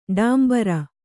♪ ḍāmbara